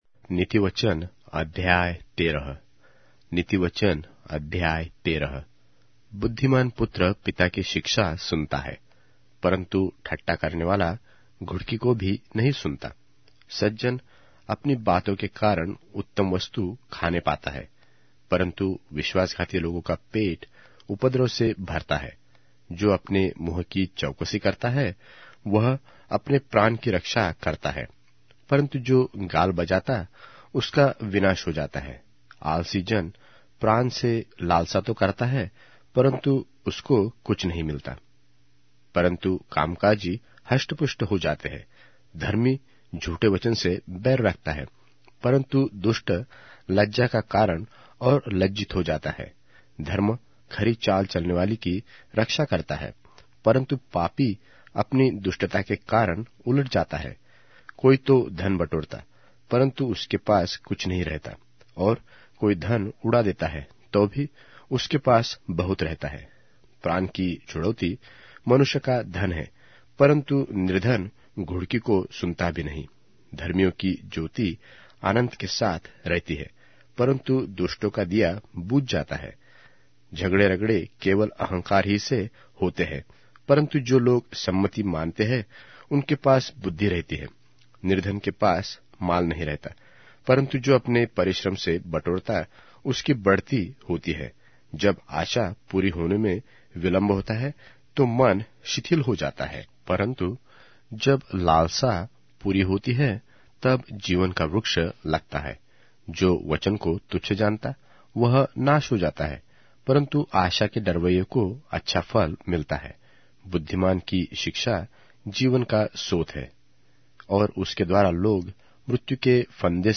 Hindi Audio Bible - Proverbs 12 in Bnv bible version